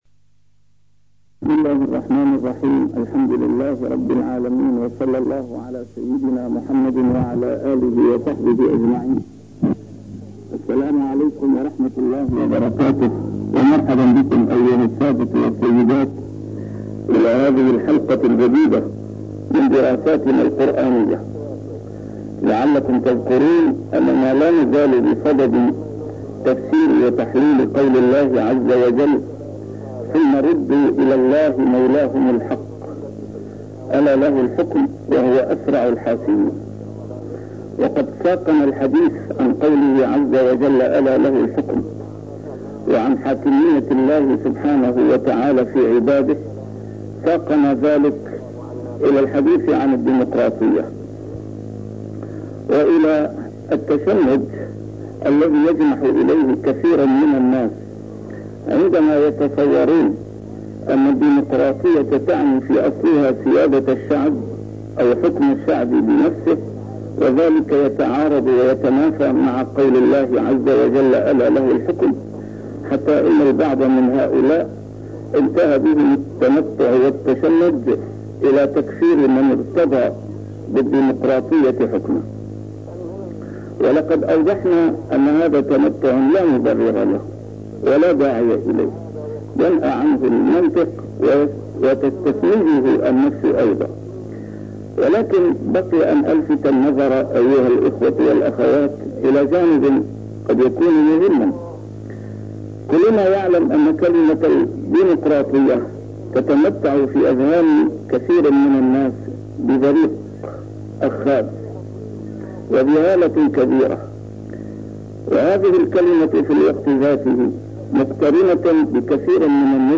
نسيم الشام › A MARTYR SCHOLAR: IMAM MUHAMMAD SAEED RAMADAN AL-BOUTI - الدروس العلمية - دراسات قرآنية - الأنعام 62 + الأنعام 63